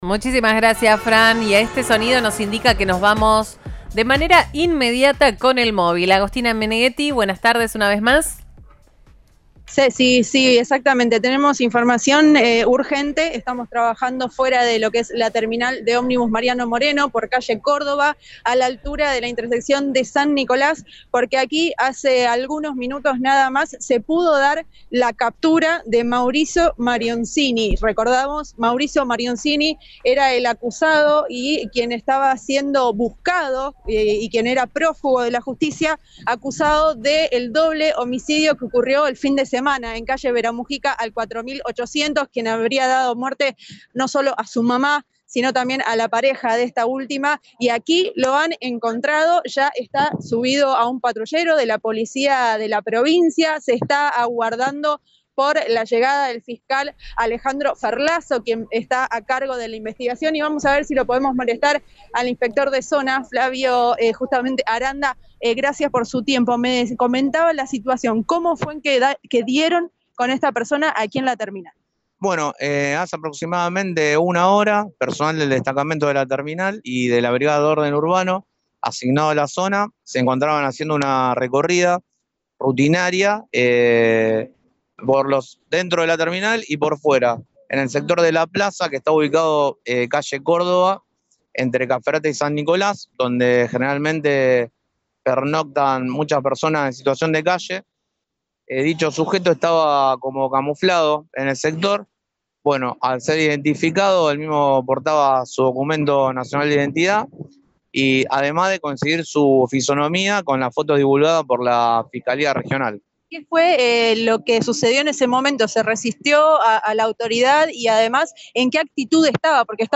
En el lugar, el fiscal Alejandro Ferlazzo dijo al móvil de Cadena 3 Rosario que al momento de la aprehensión llevaba “una computadora, un teléfono y una mochila”.
Informe